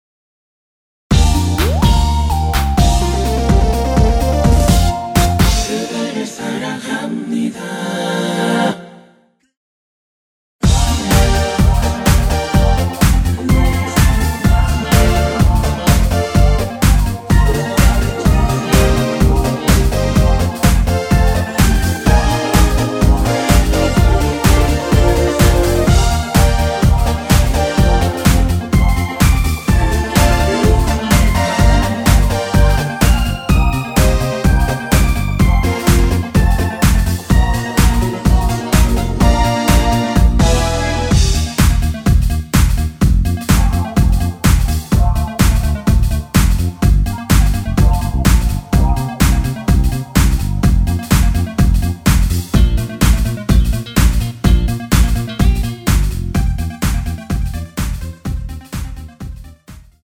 (-2) 내린 코러스 포함된 MR 입니다.(미리듣기 참조)
Bb
앞부분30초, 뒷부분30초씩 편집해서 올려 드리고 있습니다.
중간에 음이 끈어지고 다시 나오는 이유는